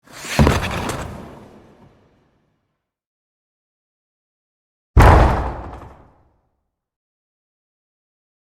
Getting CHecked into the wall
SFX
yt_XaW-i0HiQmk_getting_checked_into_the_wall.mp3